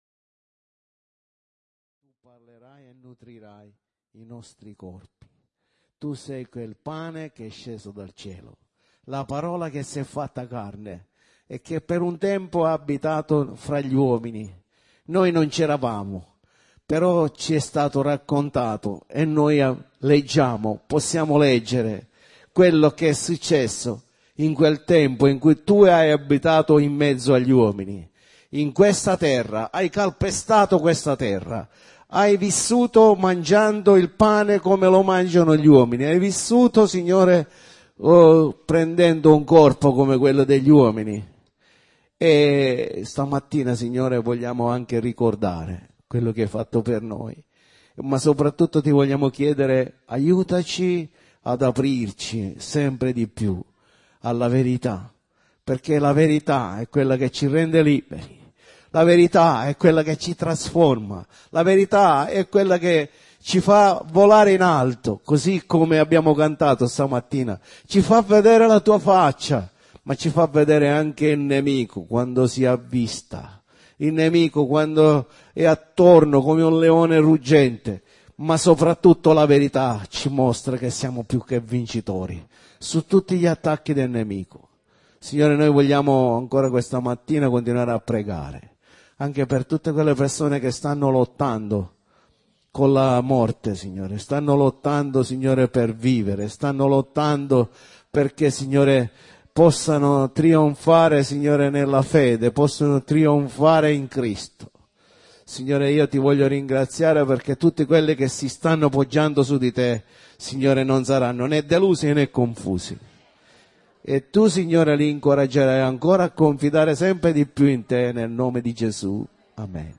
Sezione del sito per l'ascolto dei messaggi predicati la domenica e per il riascolto di studi biblici
Clicca sul messaggio audio in occasione della Cena del Signore (La Pasqua e il valore del sangue di Gesù)